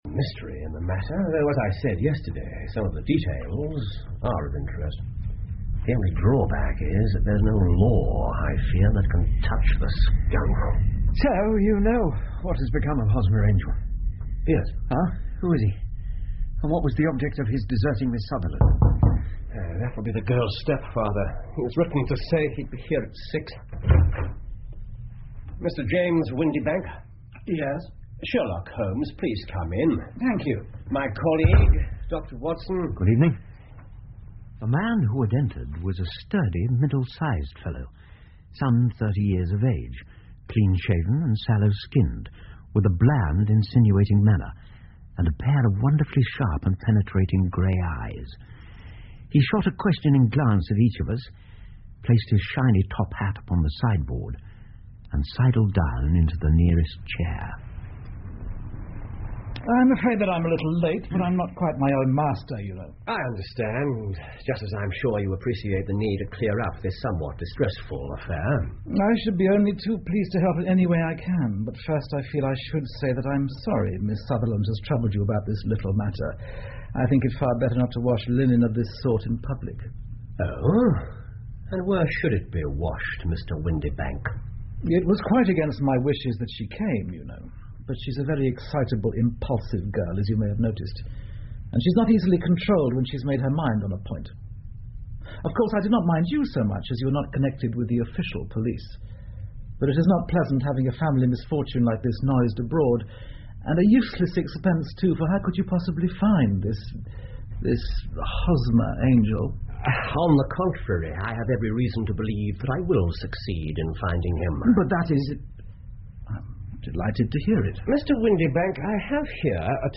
福尔摩斯广播剧 A Case Of Identity 7 听力文件下载—在线英语听力室